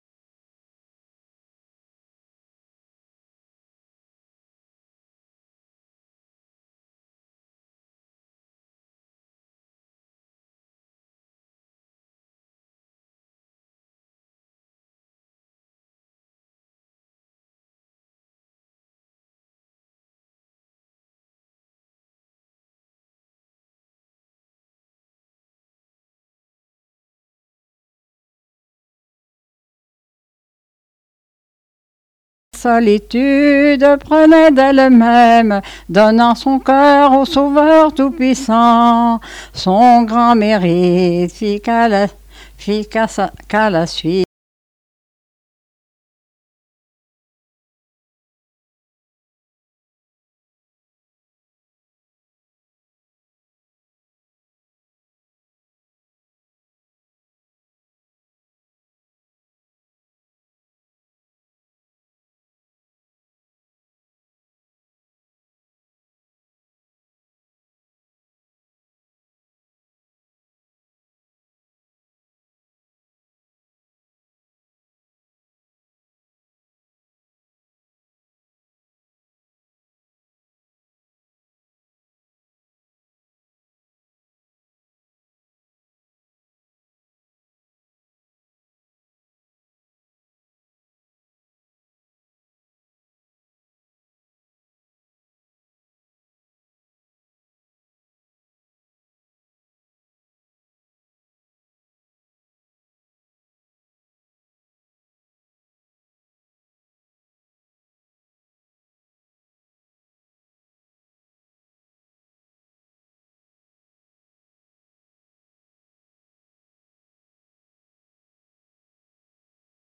Chanson
Pièce musicale inédite